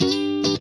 FUNKNCHUNK6H.wav